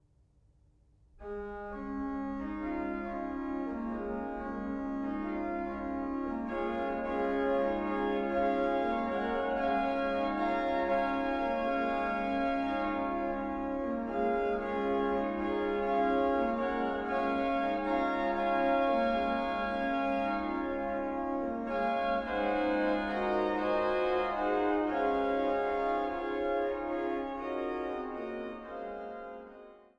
Jehmlich-Orgel in der Kirche St. Wolfgang zu Schneeberg